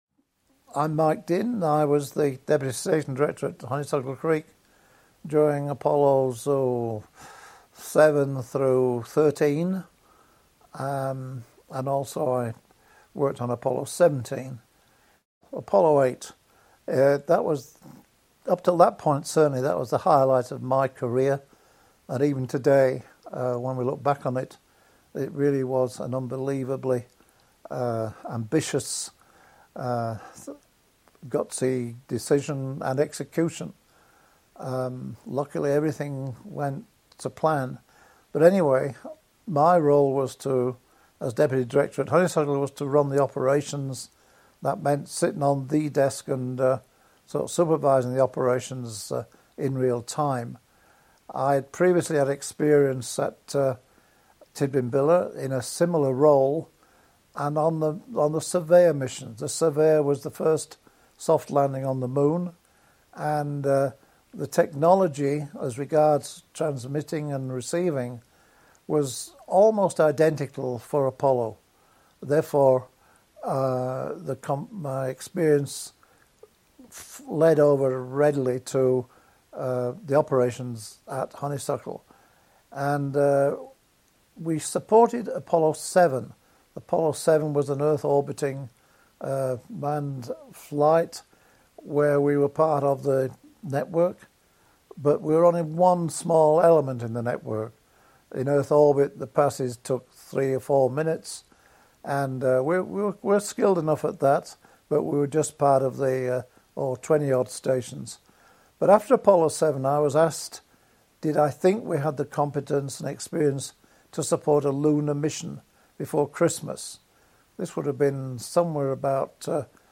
This interview was recorded in Canberra in December 2023, in time for the 55th anniversary of the mission.